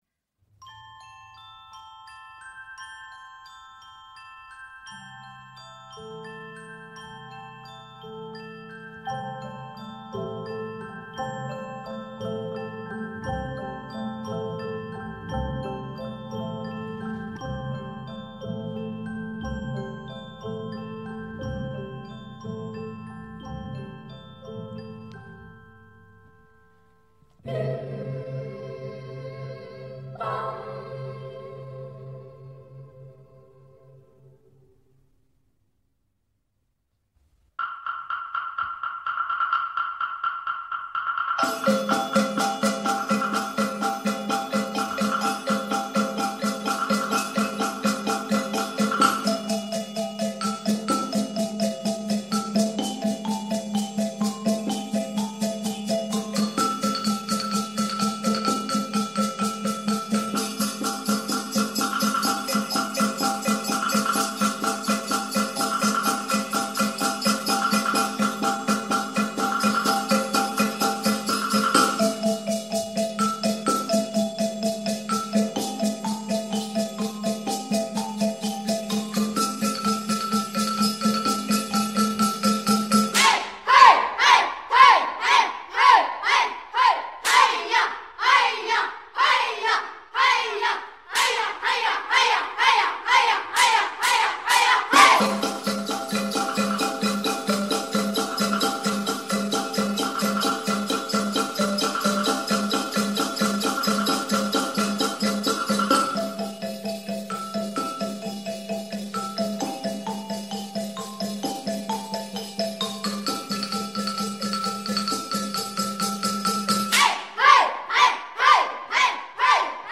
ritmodeklamaciia_taxyf0.mp3